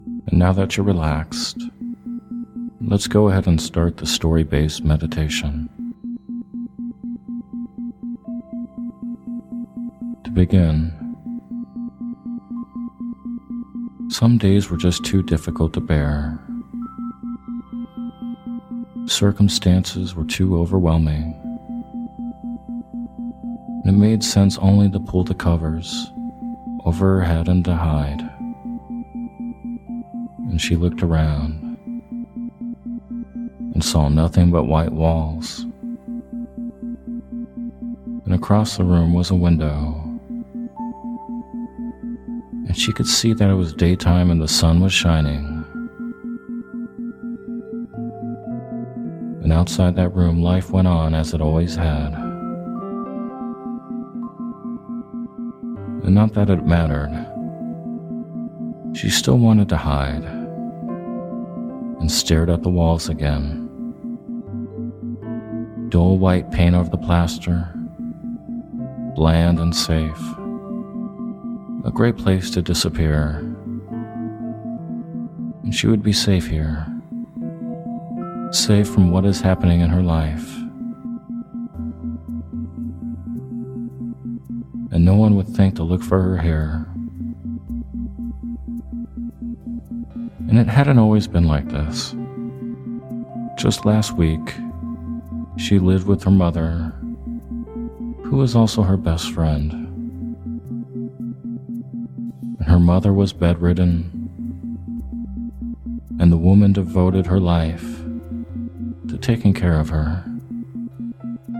Hypnosis For Healing Painful Memories With Isochronic Tones
In this day-time meditation/hypnosis audio you’ll be using mental imagery to help release the negative charge of painful memories.